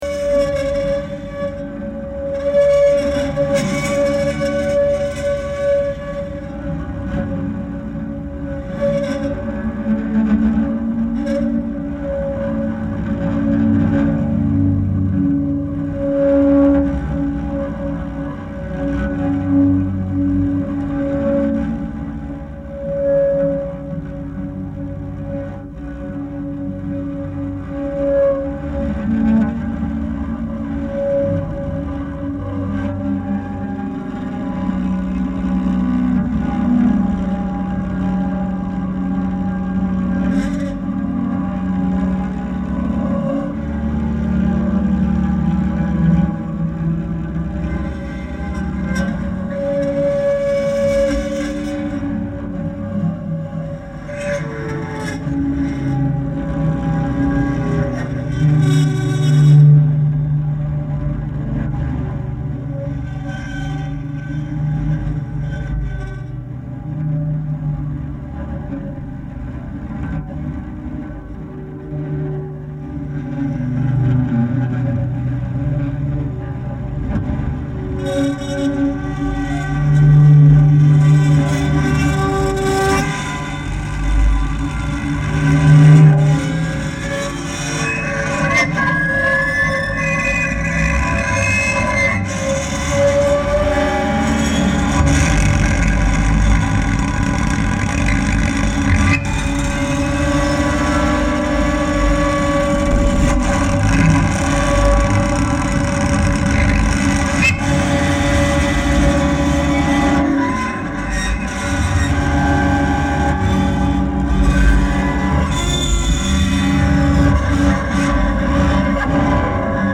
Music beyond the extremes edges of ununderstandable
Arco-Guitar-Couchée
Recorded & Mixed in Paris